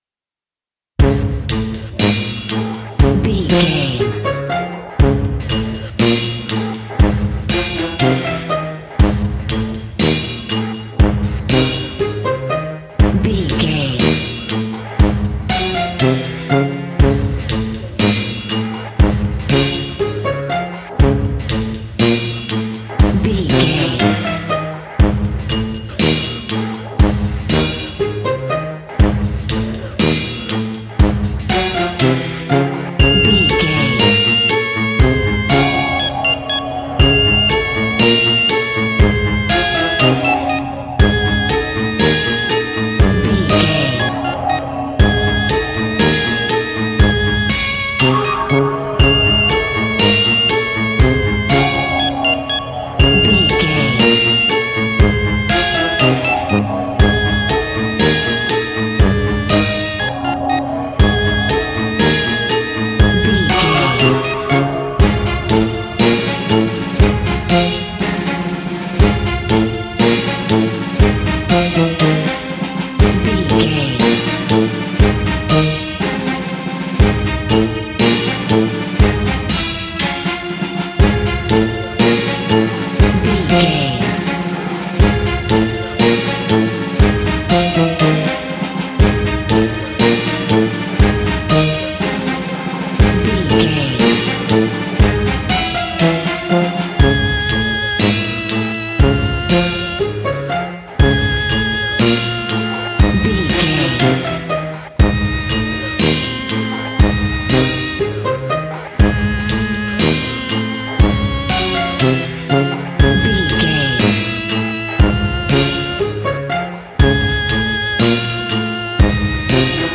Aeolian/Minor
C#
Slow
scary
ominous
eerie
playful
bouncy
percussion
brass
synthesiser
strings
instrumentals
horror music